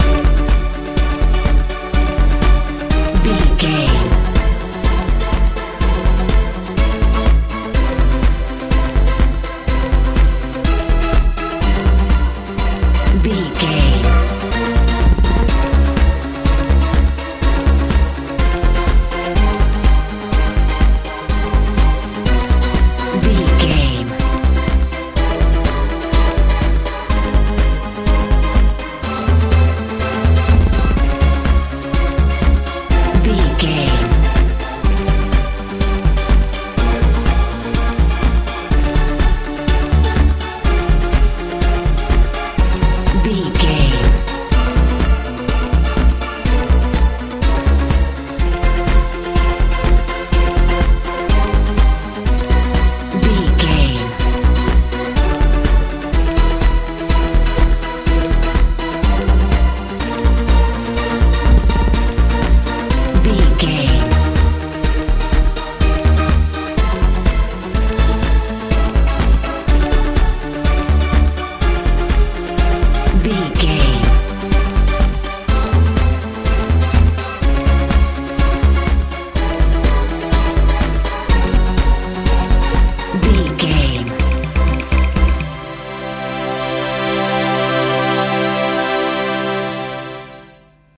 euro dance
Ionian/Major
C♯
fun
playful
drums
bass guitar
synthesiser
80s
90s